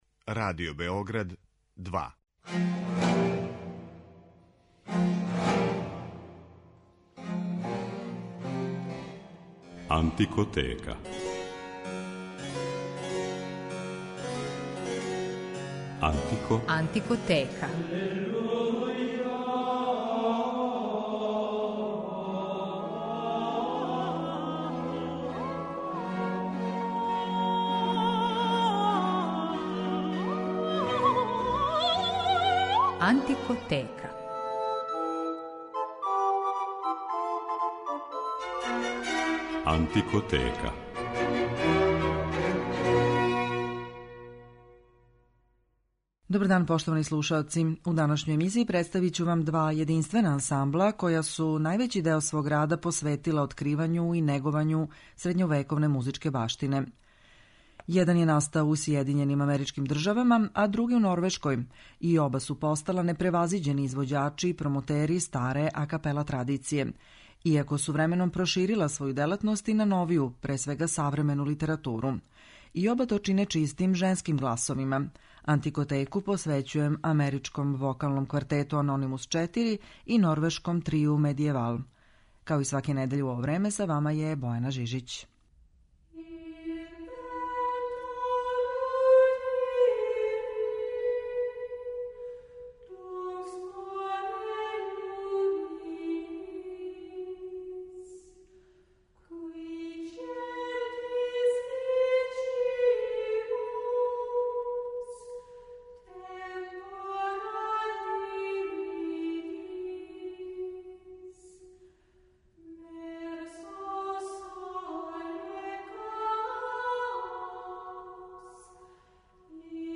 Емисија је посвећена славним ансамблима женских гласова
Као саставе анђеоских гласове описују критичари и норвешки Трио Медиевал и амерички вокални квартет Анонимус 4 који је, након 30 година рада, одлучио да се ове сезоне заувек опрости од публике.
Овим славним ансамблима женских гласова који спадају међу најбоље на свету, блиским и по репертоару и по врсти префињеног звука који негују, посвећена је данашња емисија. Слушаћете их у извођењу музике за коју су се пре свега специјализовали, духовне и световне вокалне заоставштине средњег века, али и у старој традиционалној музици својих народа.